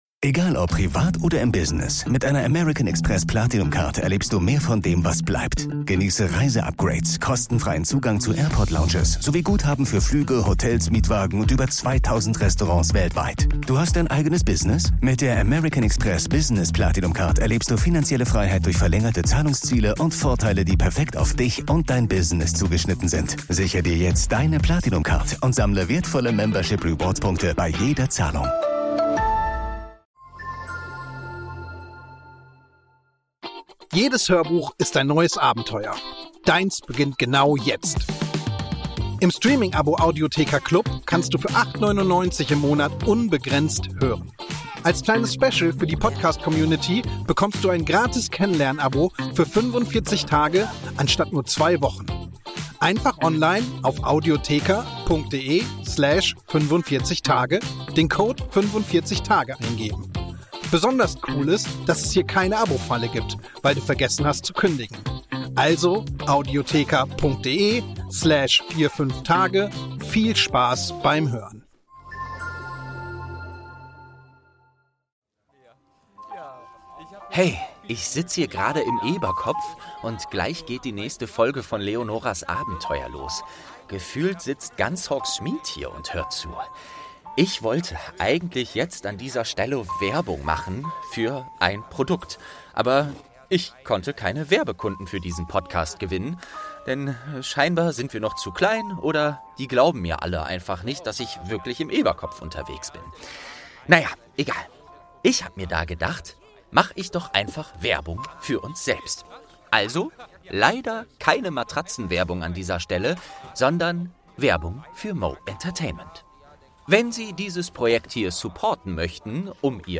19. Türchen | Charlie und seine Drachen - Eberkopf Adventskalender ~ Geschichten aus dem Eberkopf - Ein Harry Potter Hörspiel-Podcast Podcast